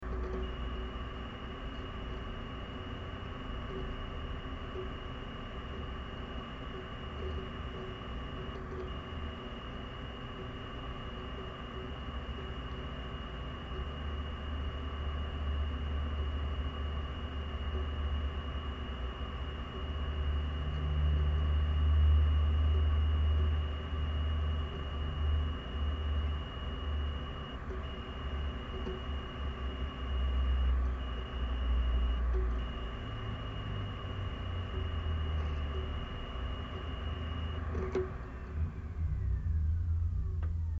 Odněkud z počítače (zavrtal jsem se dovnitř jak červ, ale ani za boha vám neřeknu, odkud to vychází) se začal ozývat velice rychle přerušovaný pískavý zvuk, který v daleko menší intenzitě důvěrně znám jak ze starého stolního PC, tak z notebooku, a jehož příčinu jsem nikdy pořádně nenašel.
Zvuky na pozadí způsobuje pevný disk, na který jsem položil mikrofon. Přerušení zvuku nastává mezi jednotlivými animovanými logy při startu hry. Bohužel není na záznamu zvuk tak intenzivní jako ve skutečnosti.
weirdsound.mp3